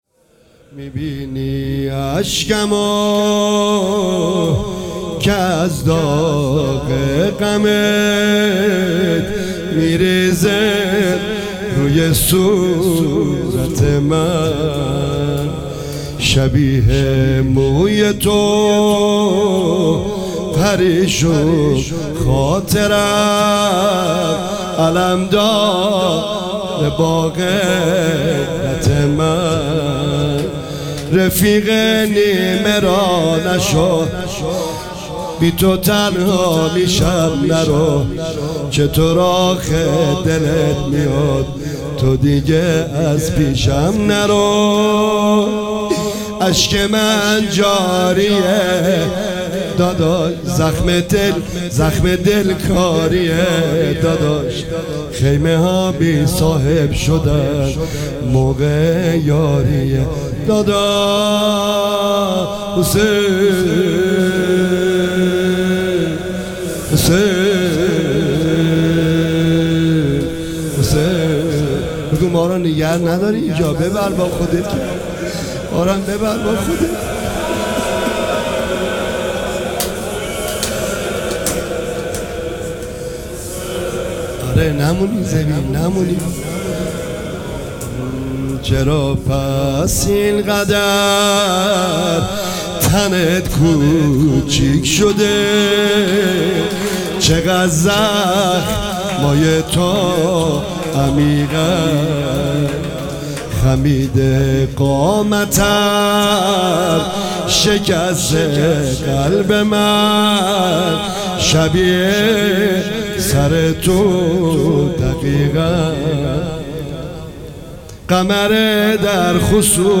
روضه روز تاسوعا نهم محرم 1404